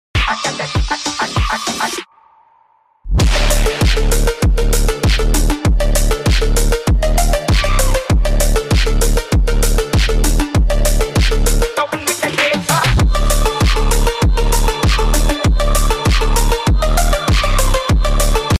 Coal Train Unloading ❗❗_R Sound Effects Free Download